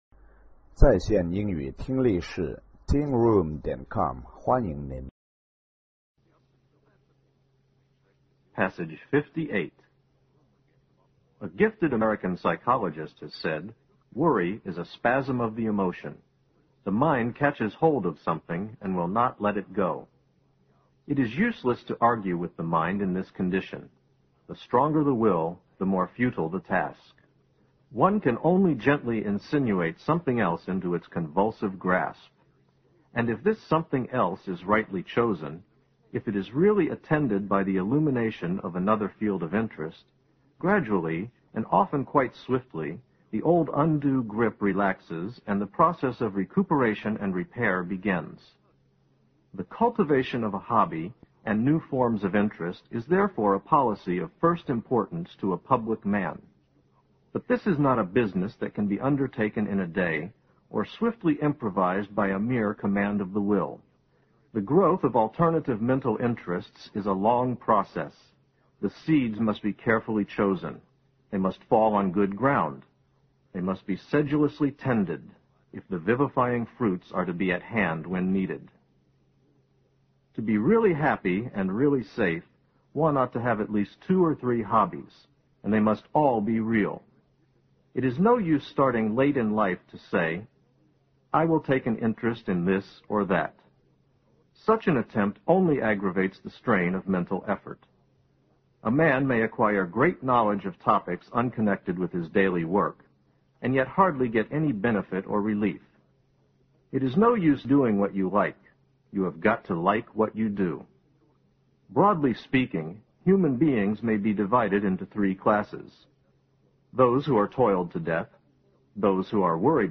新概念英语85年上外美音版第四册 第58课 听力文件下载—在线英语听力室